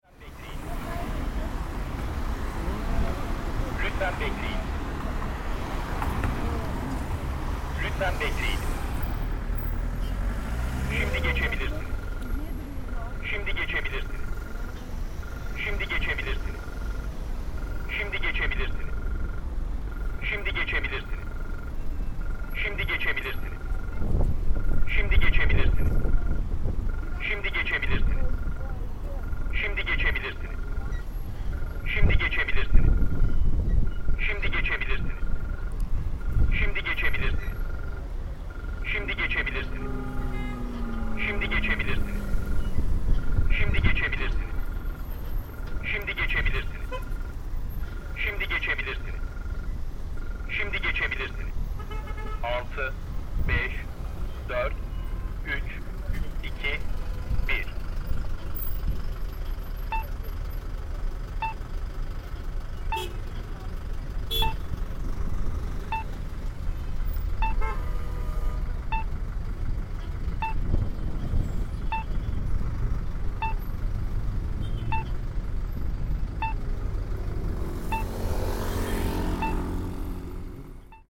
Traffic lights
Kabataş, Saturday afternoon.
• ‘bip – bip – bip – bip’ (him talking to the cars that answered)